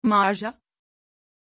Aussprache von Maarja
pronunciation-maarja-tr.mp3